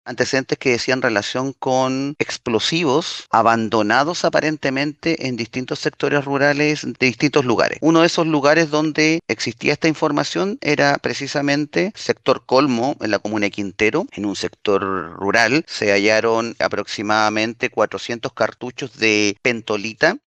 Maximiliano Krause, fiscal de la Unidad Regional de Análisis Criminal y Focos Investigativos, detalló que la investigación al respecto se extendía desde hace de un año y medio aproximadamente.